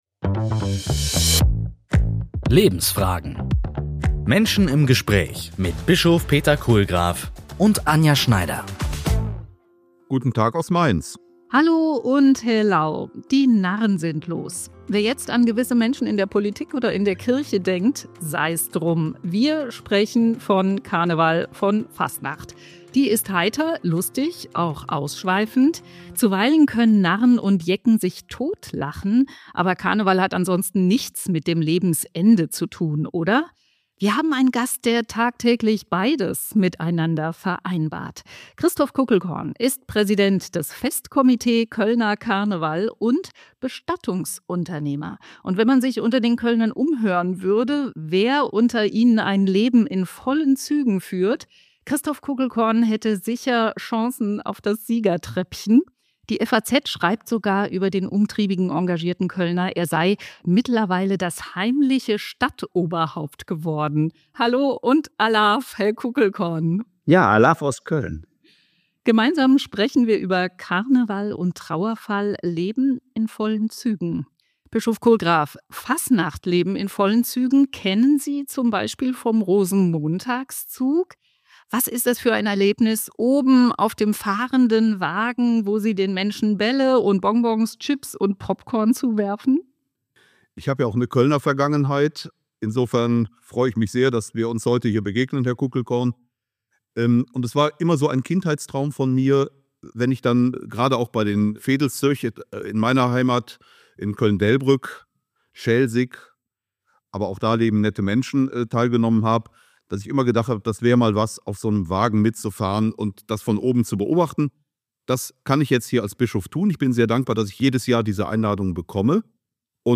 Lebensfragen - Menschen im Gespräch